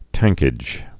(tăngkĭj)